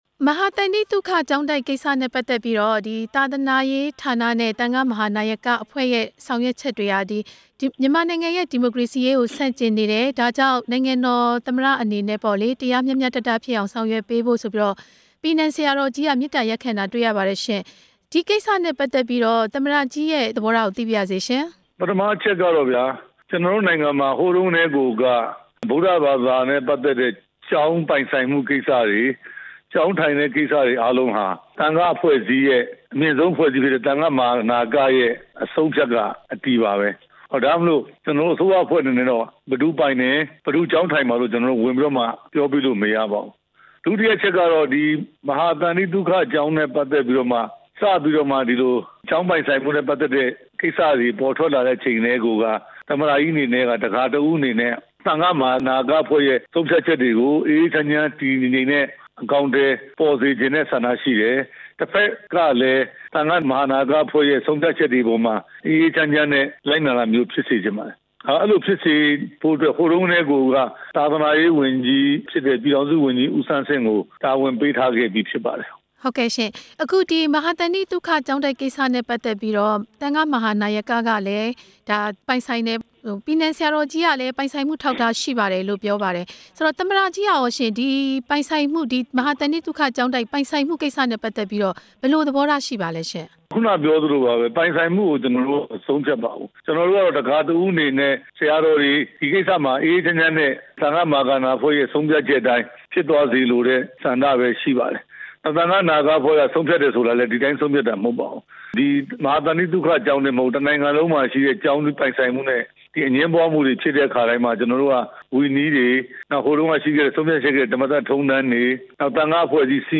သမ္မတရဲ့ ပြောခွင့်ရပုဂ္ဂိုလ် ဒုဝန်ကြီး ဦးရဲထွဋ်ကို ဆက်သွယ်မေးမြန်းချက်
ရန်ကုန်တိုင်း တာမွေမြို့နယ်က မဟာသန္တိသုခကျောင်းတိုက် ချိတ်ပိတ်ခံရပြီး ကျောင်းတိုက်က သံဃာငါးပါးကို ဖမ်းဆီးလူဝတ်လဲ ထိန်းသိမ်းထားမှုကို ဝေဖန်မှုတွေ ထွက်ပေါ်နေတာနဲ့ ပတ်သက်ပြီး နိုင်ငံတော်သမ္မတရဲ့ ပြောခွင့်ရပုဂ္ဂိုလ် ပြန်ကြားရေးဝန်ကြီးဌာန ဒုတိယဝန်ကြီး ဦးရဲထွဋ်ကို ဆက်သွယ်မေးမြန်းထားပါတယ်။